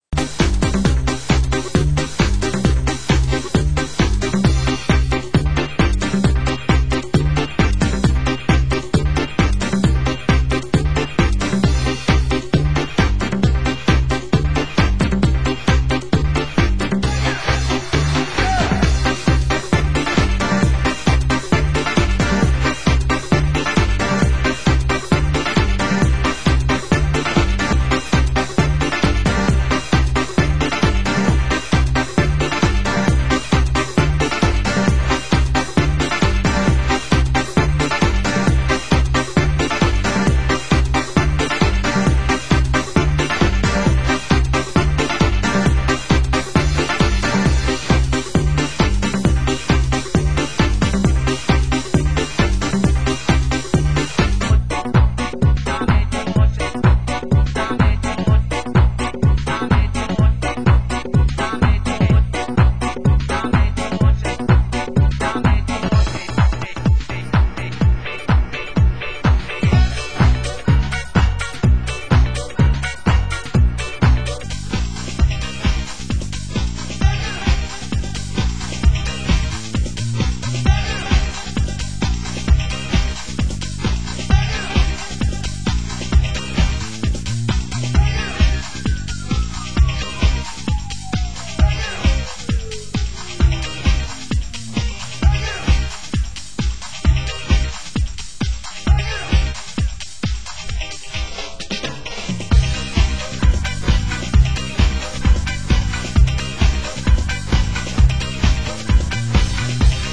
Genre Euro House